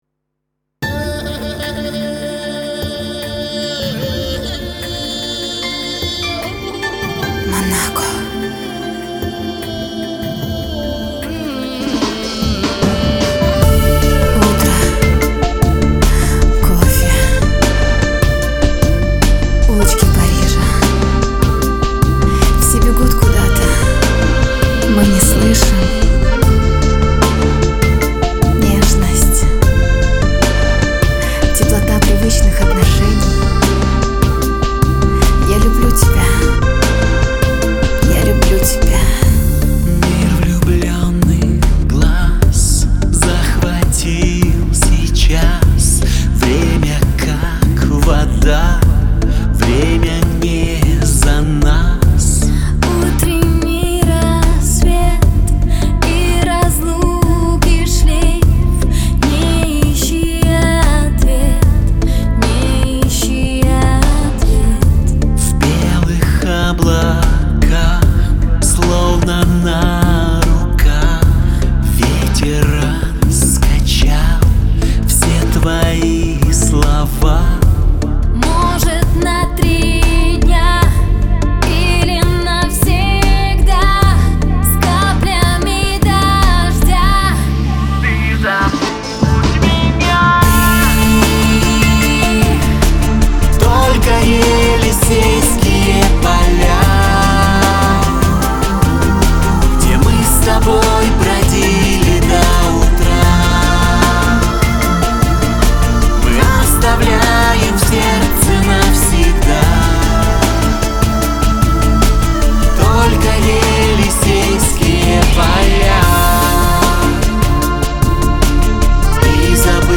Категория: Грустные песни